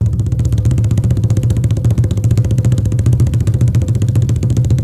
-ZULU ROLL-R.wav